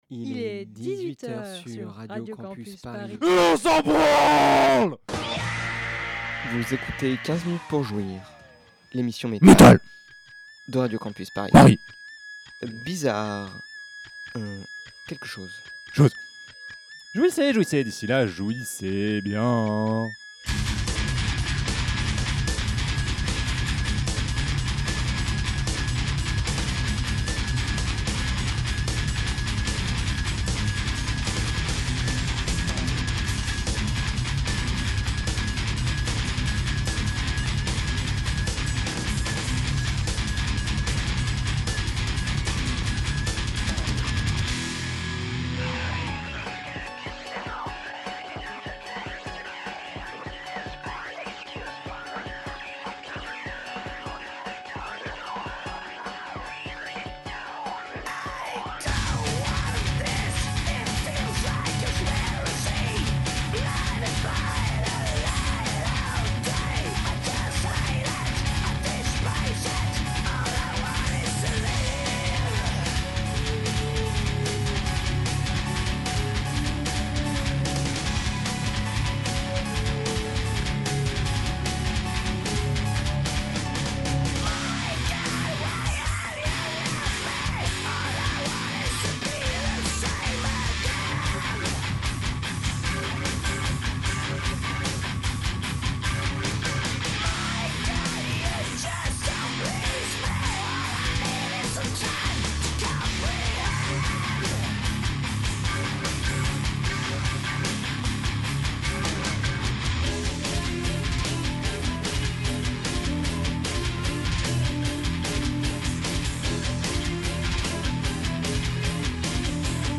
Avant-Garde